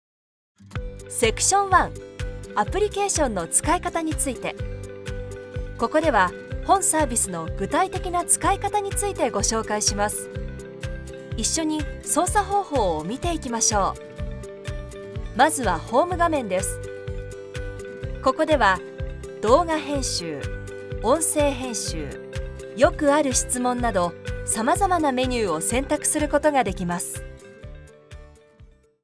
Kommerziell, Tief, Zuverlässig, Freundlich, Corporate
E-learning
Her voice is versatile, stylish, luxurious, authentic, yet believable